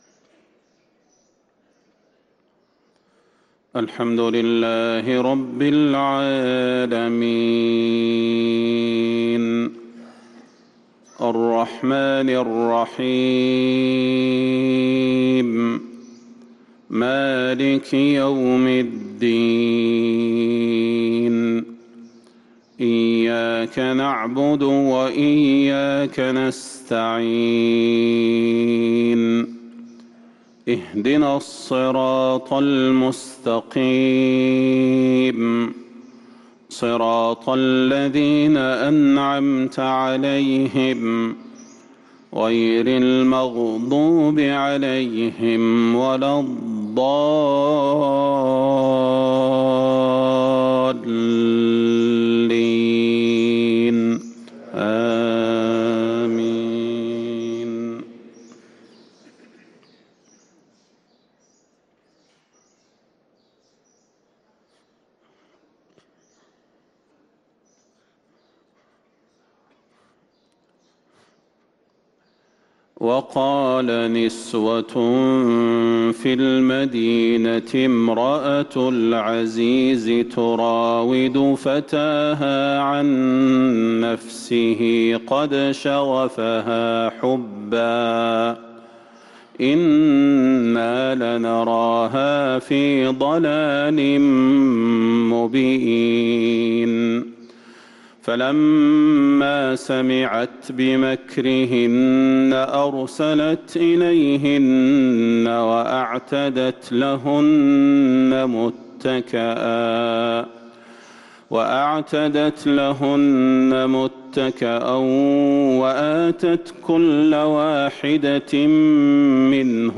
صلاة الفجر للقارئ صلاح البدير 7 رجب 1444 هـ
تِلَاوَات الْحَرَمَيْن .